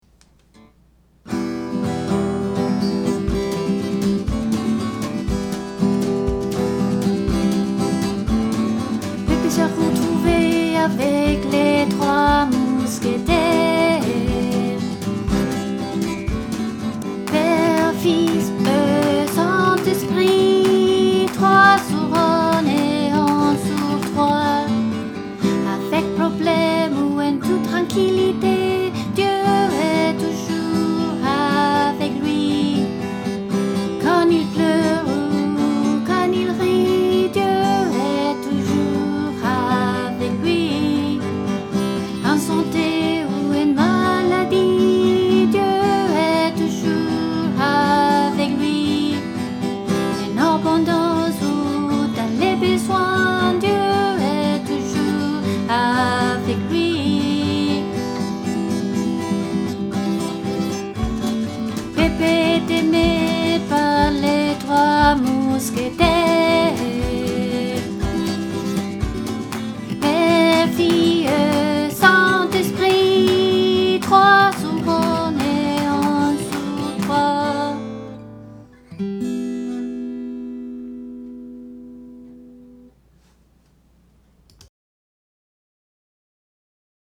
guitare